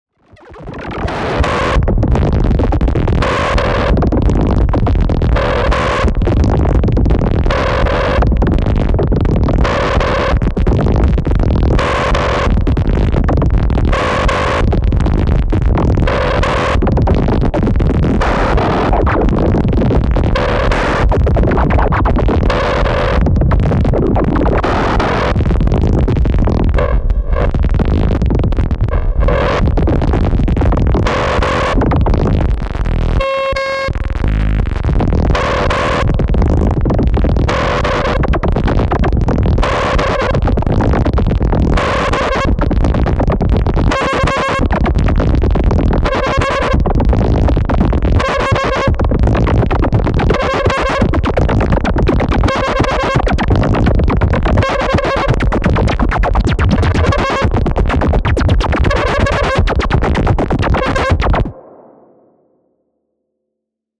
0-Coast / SQ-1 / Romb